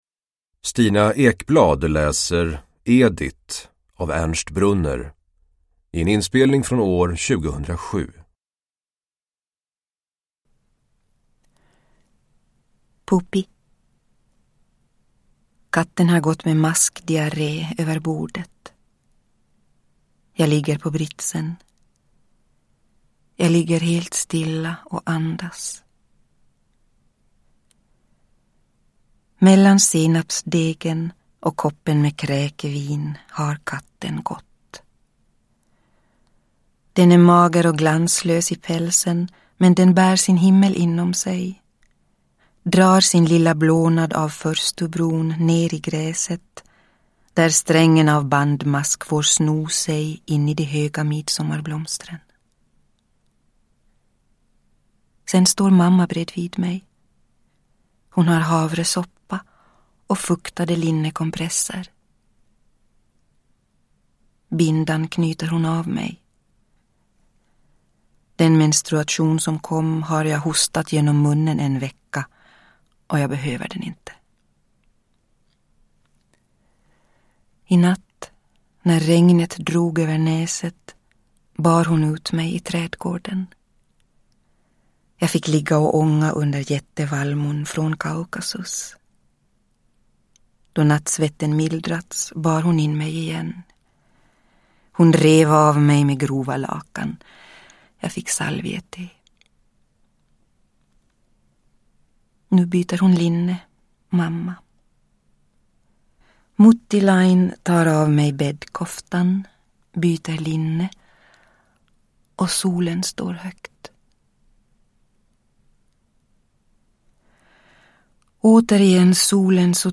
Uppläsare: Stina Ekblad
Ljudbok